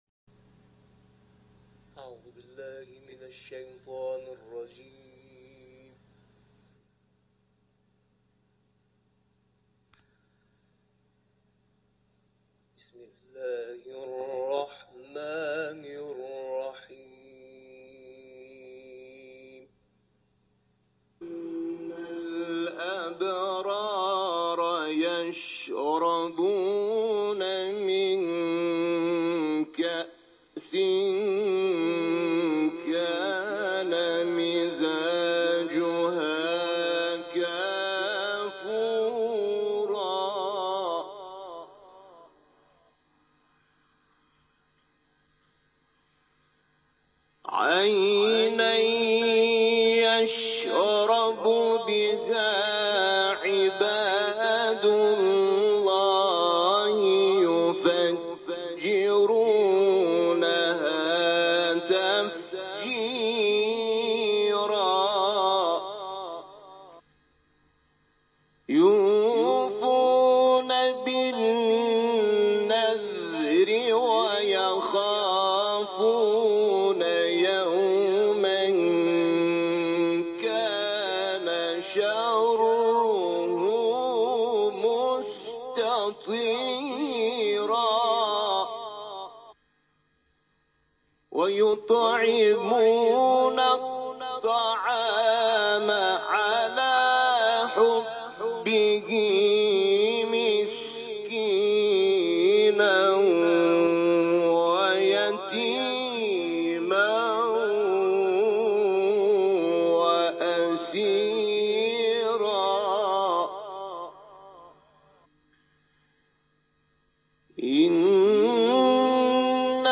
صوت تلاوت